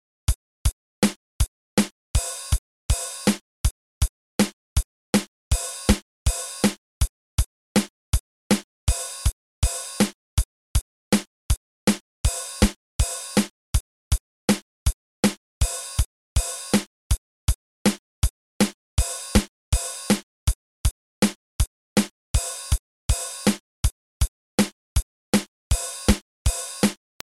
The snare on the + of 8 breaks the perfect symmetry of the kick, snare, and hat rhythm. Minus that, the rhythm from the opening downbeat through 5 is duplicated, beginning on the + of 5, through the + of 9. Note that it’s offset by one eighth note, causing downbeats to fall on upbeats and vice-versa.